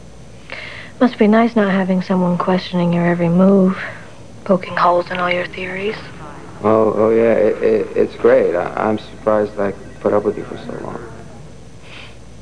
Sound Effects for Windows
1 channel
pokehole.mp3